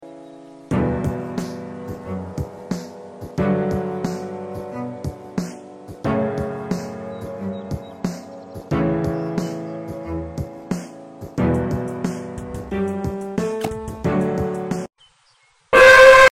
You can never escape the screaming ones